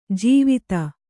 ♪ jīvita